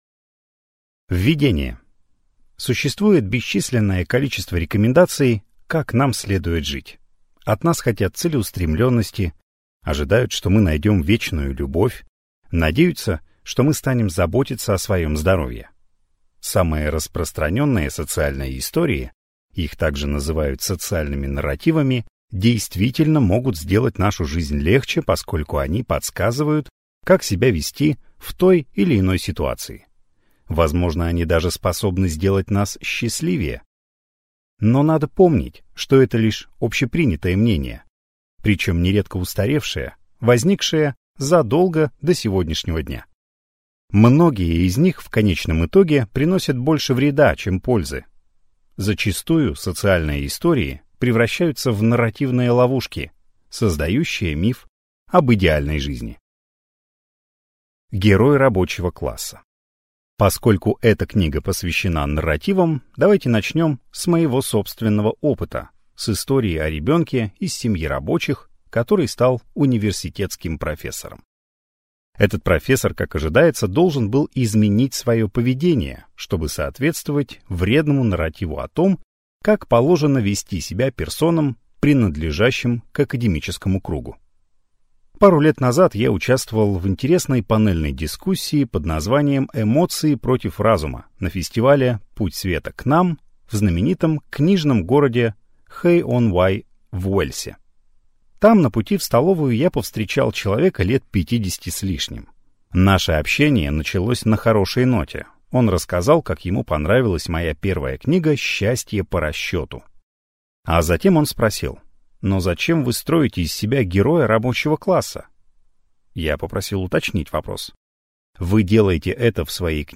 Аудиокнига Счастливы когда-нибудь | Библиотека аудиокниг